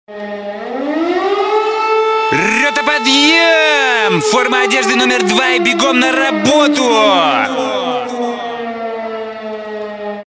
Звуки звонка, будильника